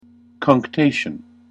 /kʌŋkˈteɪʃən(英国英語)/